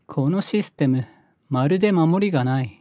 ハッカー ボイス 声素材 – Hacker Cracker Voice
Voiceボイス声素材